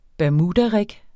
Udtale [ bæɐ̯ˈmuːdaˌʁεg ]